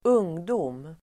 Uttal: [²'ung:dom:]